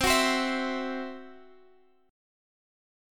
Listen to CMb5 strummed